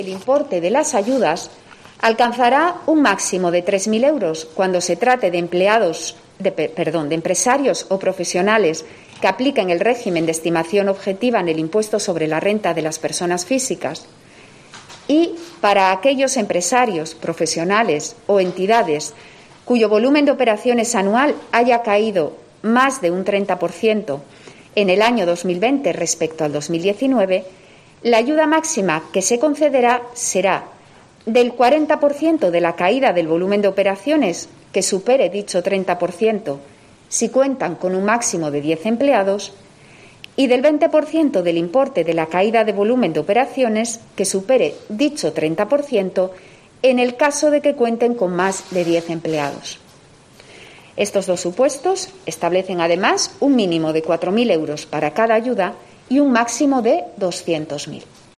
Ana Cárcaba explica el reparto de las ayudas